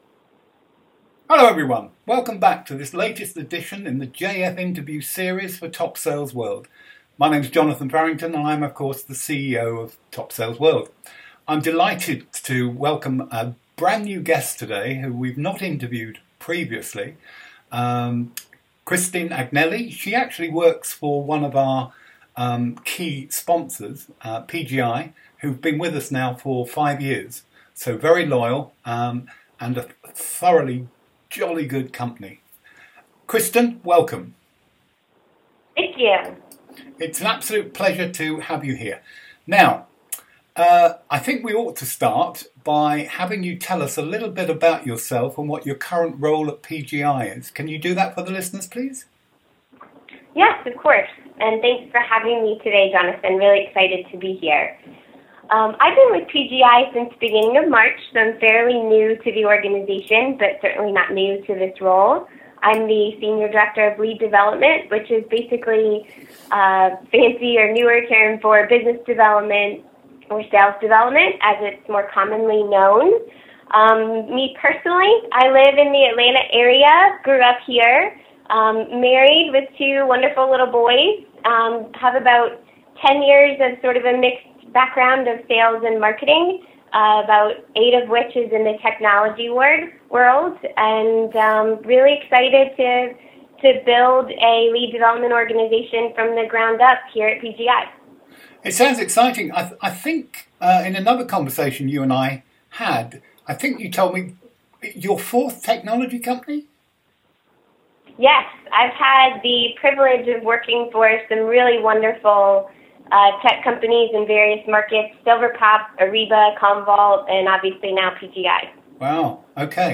Category: Interview, Lead Generation, Sales Prospecting